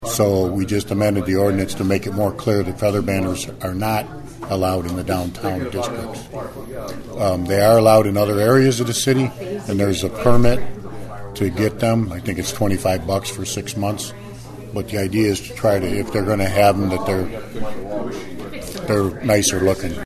Ottawa’s ban on feather banners downtown continues. The City Council tweaked the ordinance this week though. Mayor Dan Aussem says it wasn’t so clear just what was banned.